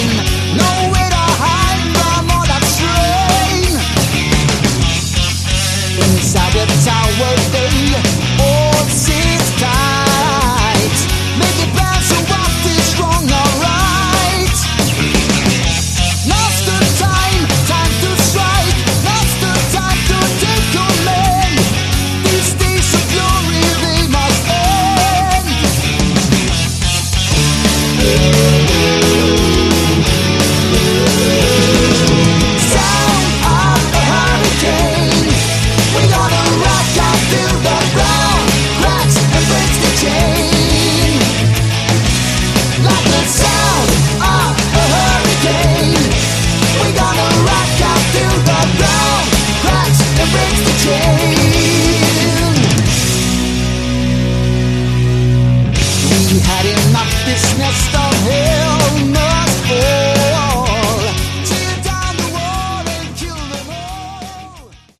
Category: Hard Rock
vocals
guitars
bass
drums
keyboards